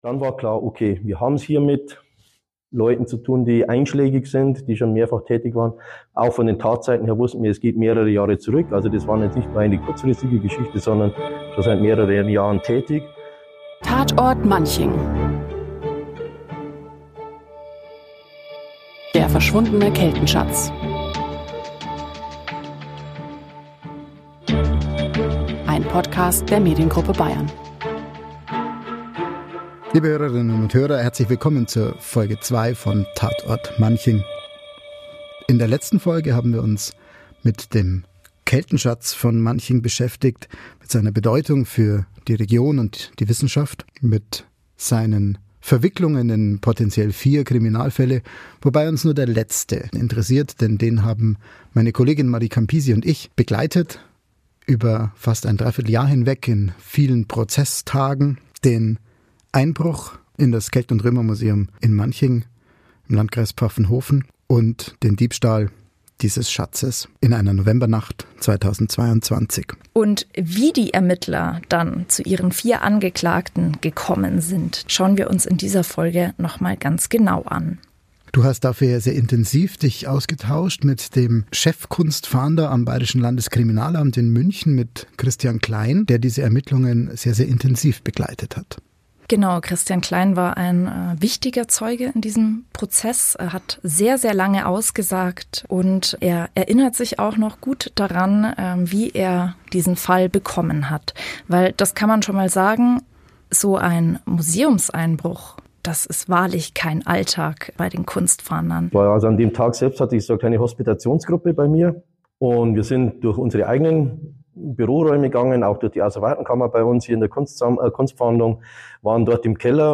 In dieser Episode kommen die Köpfe hinter der Fahndung zu Wort.